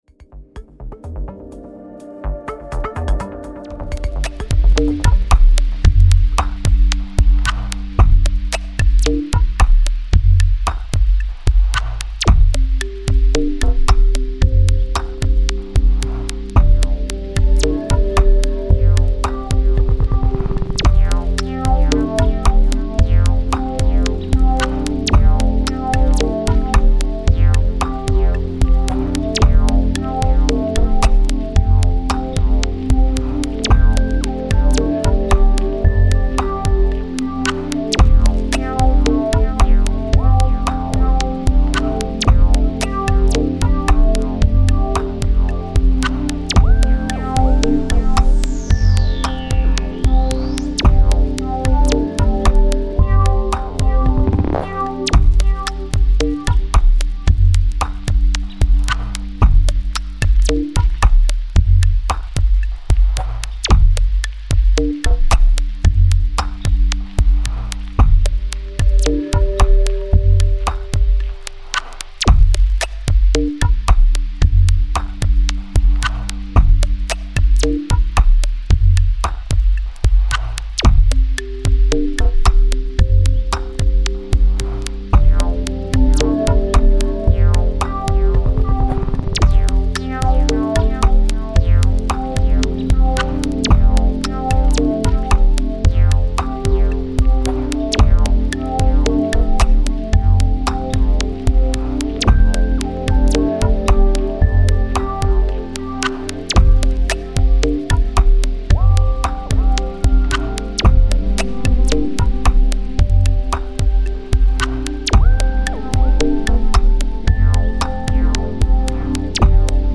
Поджанр музыки Chilout. Музыка для отдыха.